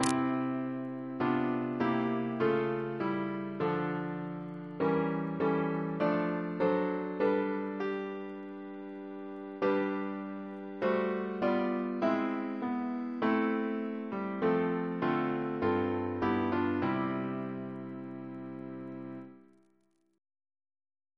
Double chant in C Composer: Thomas Tertius Noble (1867-1953) Reference psalters: RSCM: 102